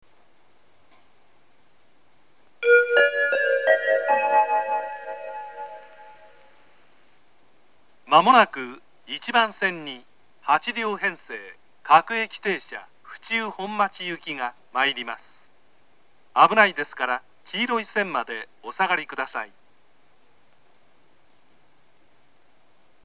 武蔵野ＰＲＣ型放送時
１番線接近放送
自動放送は武蔵野ＰＲＣ型放送でした。